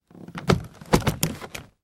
Звуки чемодана
Открыли верхний отдел чемодана